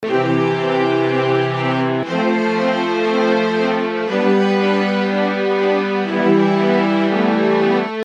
大提琴层数
标签： 120 bpm Hip Hop Loops Strings Loops 1.35 MB wav Key : Unknown
声道立体声